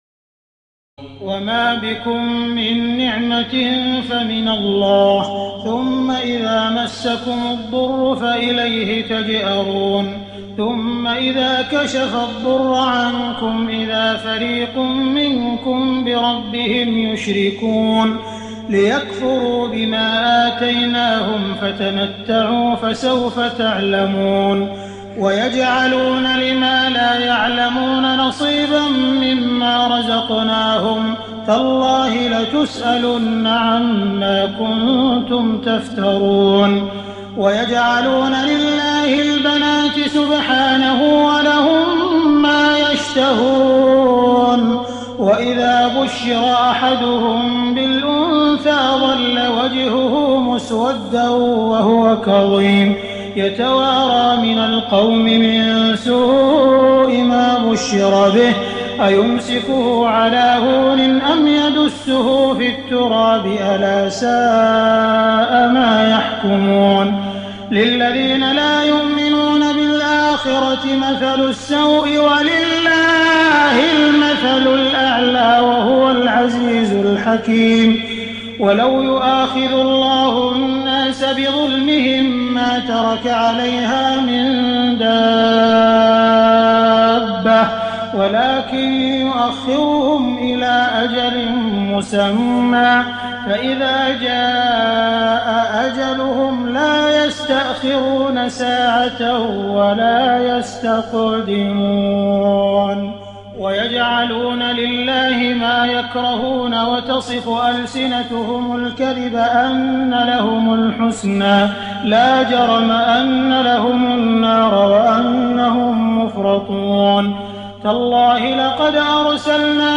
تراويح الليلة الثالثة عشر رمضان 1419هـ من سورة النحل (53-128) Taraweeh 13 st night Ramadan 1419H from Surah An-Nahl > تراويح الحرم المكي عام 1419 🕋 > التراويح - تلاوات الحرمين